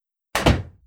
close-door.wav